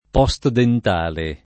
vai all'elenco alfabetico delle voci ingrandisci il carattere 100% rimpicciolisci il carattere stampa invia tramite posta elettronica codividi su Facebook postdentale [ p qS t dent # le ] (raro posdentale ) agg. (ling.)